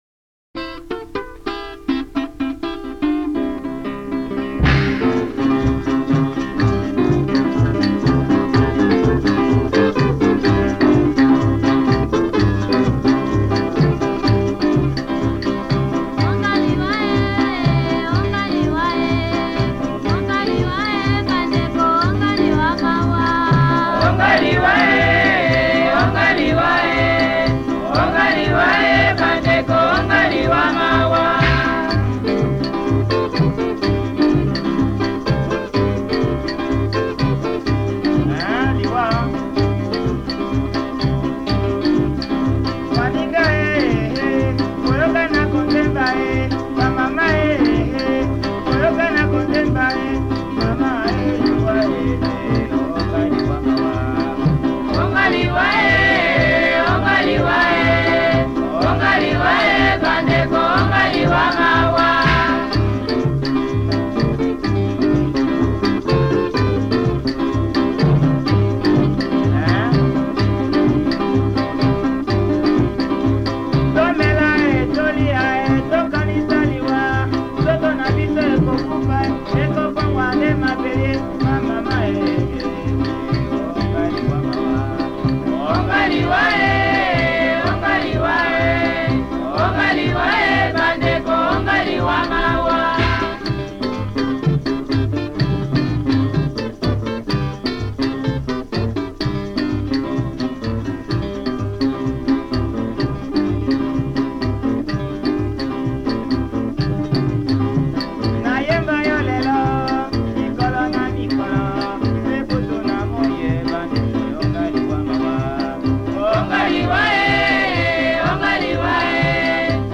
la etiquetaron como polka piké.